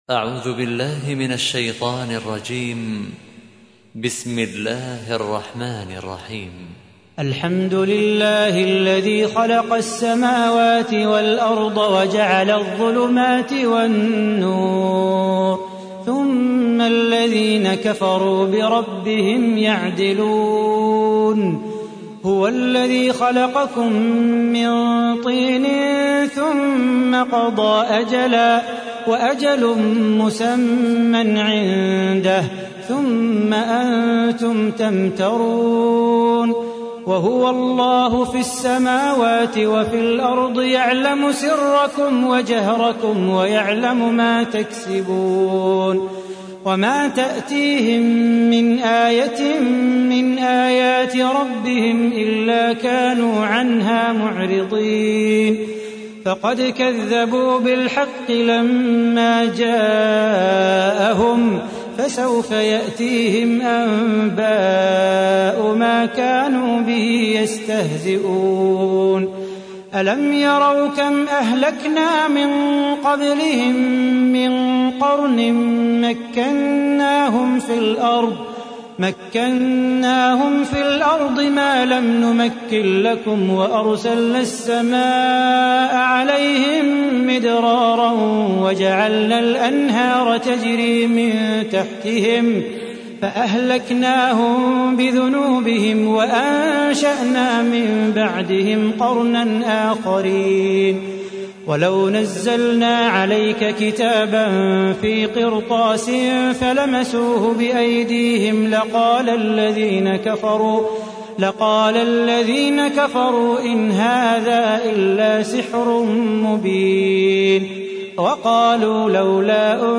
تحميل : 6. سورة الأنعام / القارئ صلاح بو خاطر / القرآن الكريم / موقع يا حسين